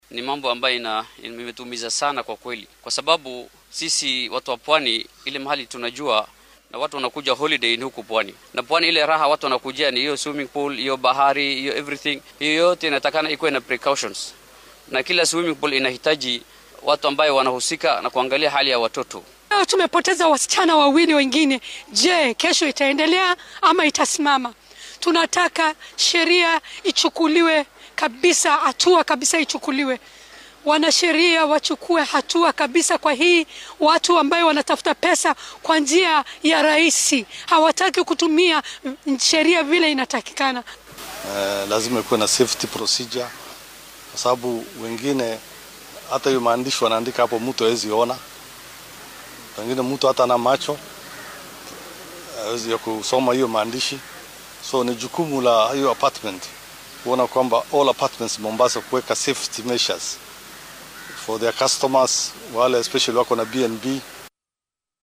Qaar ka mid ah ehellada iyo mas’uuliyiinta oo arrintan ka hadlay ayaa yidhi.